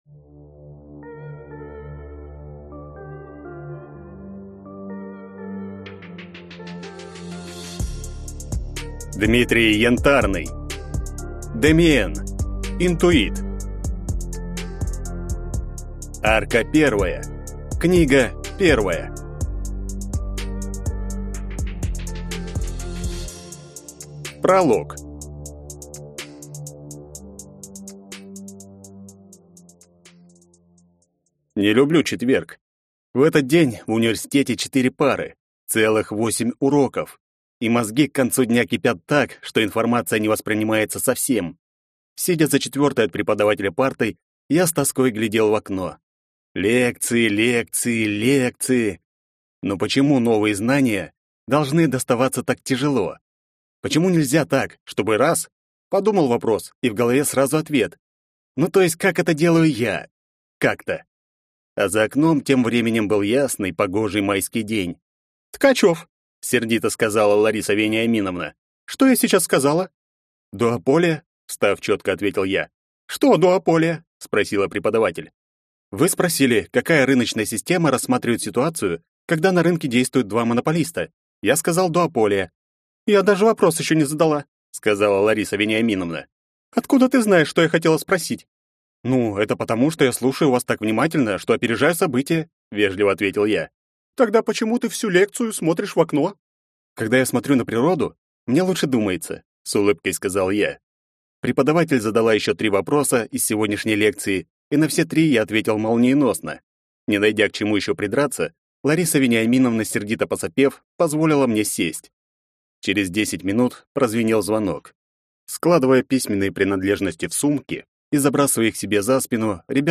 Аудиокнига Интуит. Арка 1 | Библиотека аудиокниг